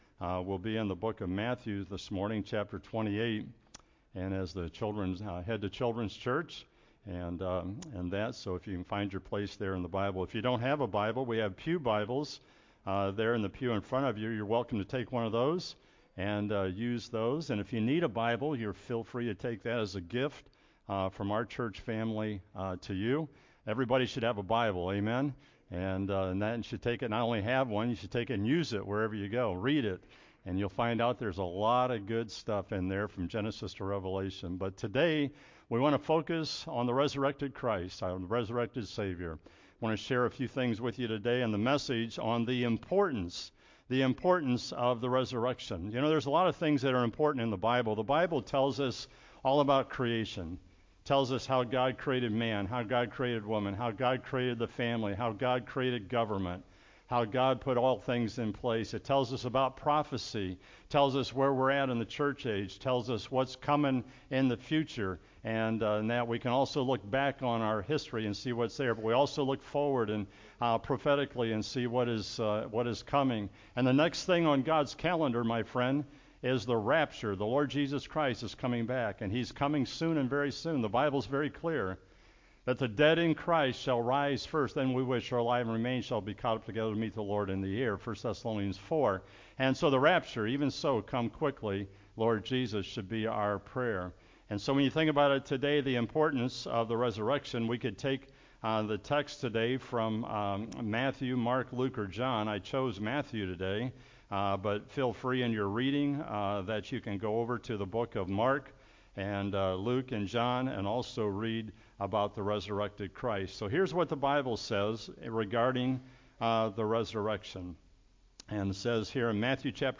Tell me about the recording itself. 4-9-23 A.M. Easter Service